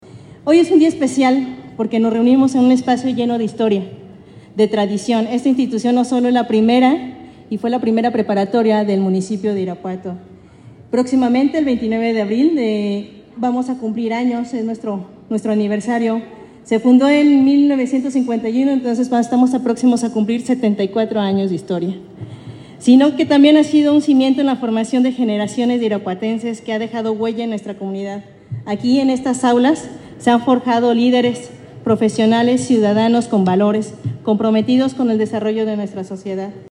Irapuato, Gto. 18 de febrero del 2025 .- Para promover los valores cívicos, el Gobierno Municipal llevó a cabo los honores a la bandera con estudiantes y maestros de la Escuela de Nivel Medio Superior de Irapuato (ENMSI).